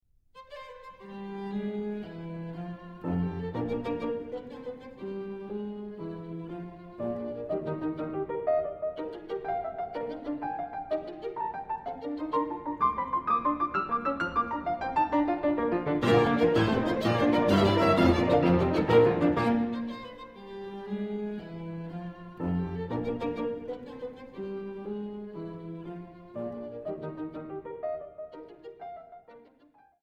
performance is vivid and dynamic